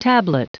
Prononciation du mot tablet en anglais (fichier audio)
Prononciation du mot : tablet